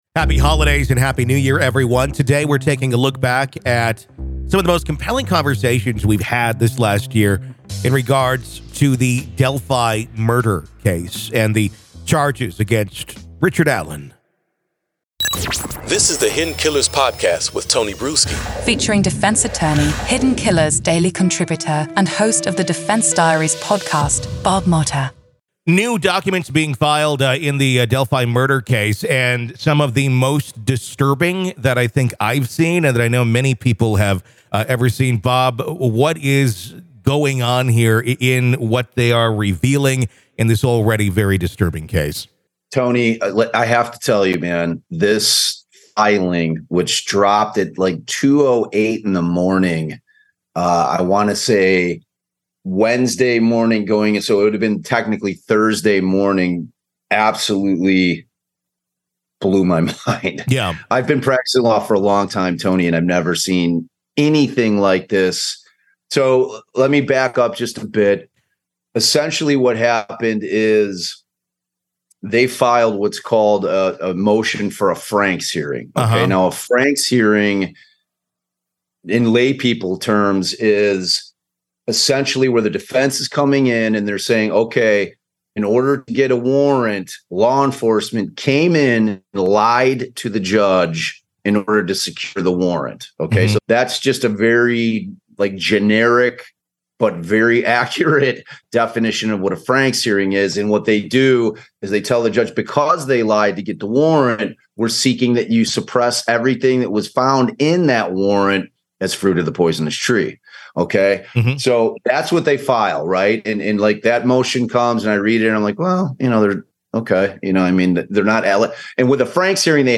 From seasoned investigators and FBI agents who've painstakingly combed through evidence, to defense attorneys and prosecutors offering unique legal insights, these expert interviews have provided a comprehensive understanding of the case.